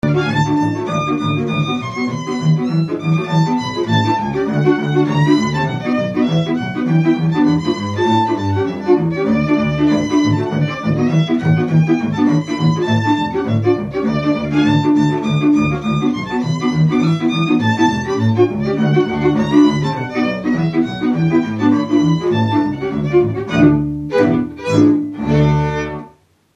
Dallampélda: Hangszeres felvétel
Felföld - Heves vm. - Felsőtárkány
hegedű
kontra
bőgő
Műfaj: Friss csárdás
Stílus: 1.1. Ereszkedő kvintváltó pentaton dallamok
Kadencia: 6 (3) 4 1